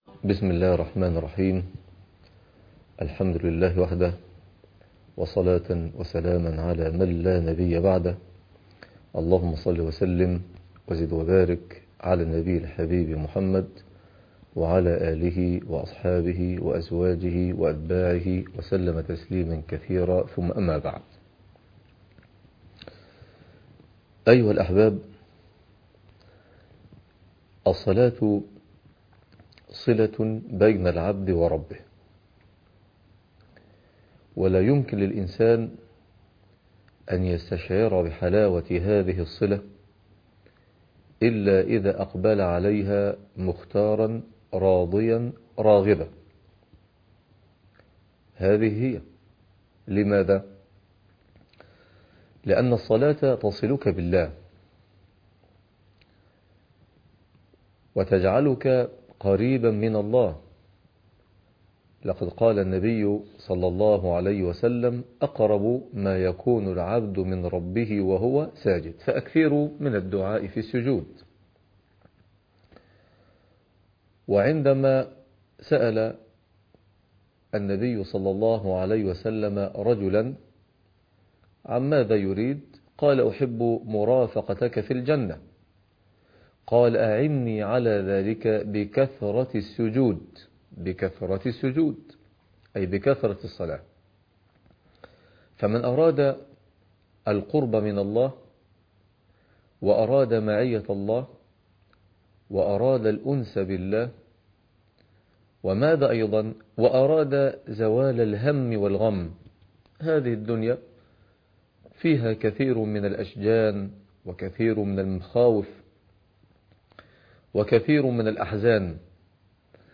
عنوان المادة 05 - الدرس الخامس من برنامج محاسن الإسلام - الصلاة تاريخ التحميل الثلاثاء 10 يناير 2023 مـ حجم المادة 9.22 ميجا بايت عدد الزيارات 297 زيارة عدد مرات الحفظ 118 مرة إستماع المادة حفظ المادة اضف تعليقك أرسل لصديق